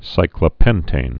(sīklə-pĕntān, sĭklə-)